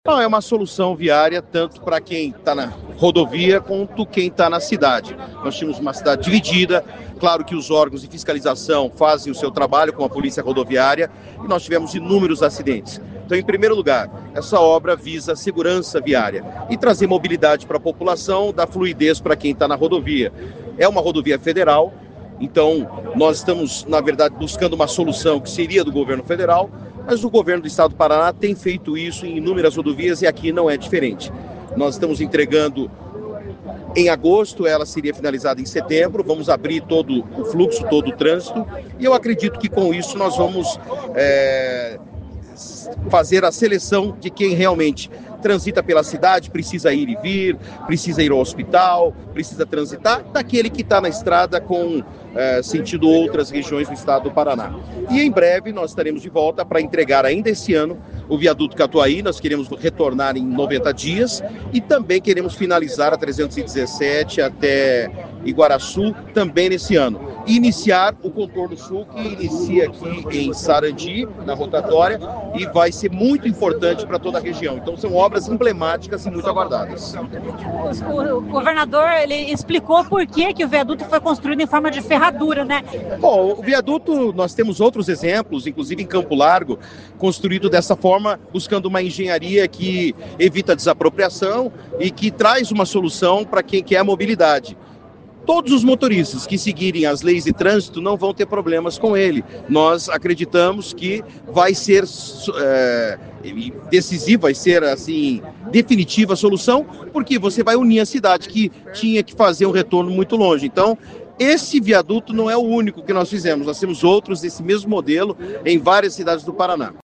O secretário de Infraestrutura e Logística do Paraná Sandro Alex também participou da inauguração dos viadutos e falou sobre a obra de engenharia, uma solução viária que garante segurança e fluidez. A rodovia é federal, mas a solução viária foi do Estado.